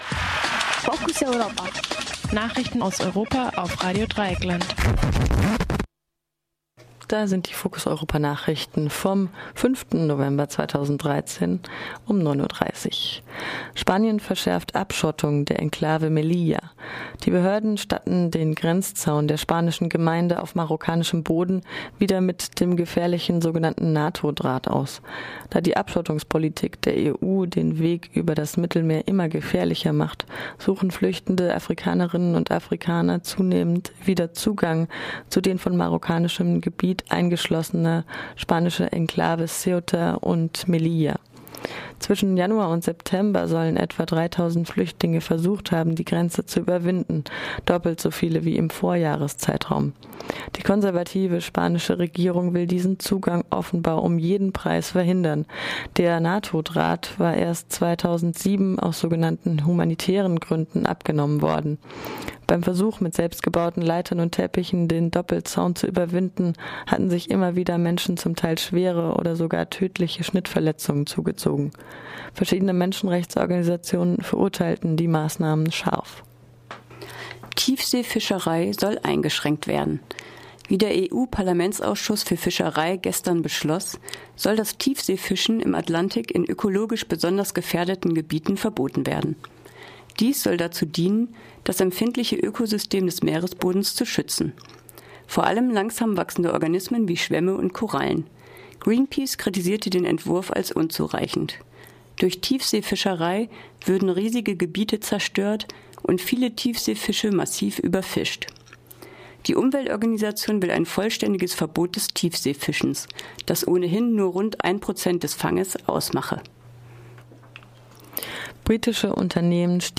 Focus Europa Nachrichten vom Dienstag, den 5. November - 9.30 Uhr